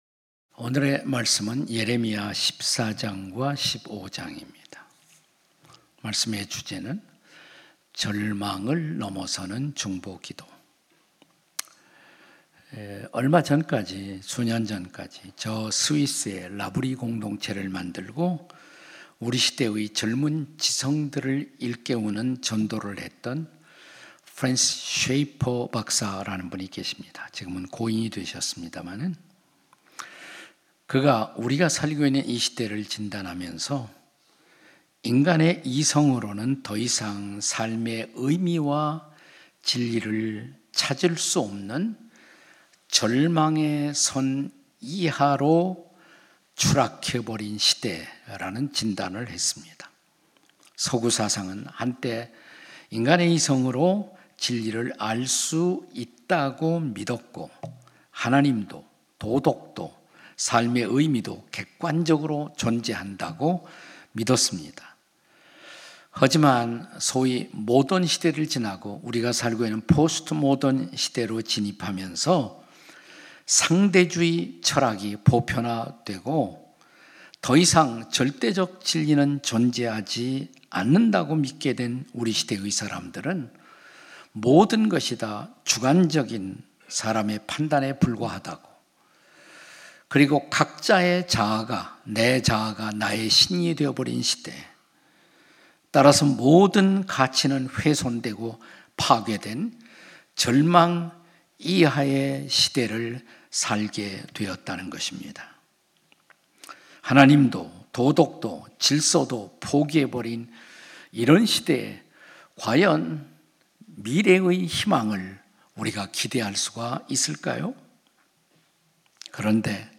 설교 : 주일예배 예레미야 - (12) 절망을 넘어서는 중보기도